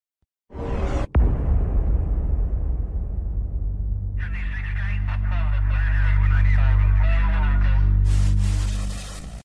this is a sound byte from an intro of an EA game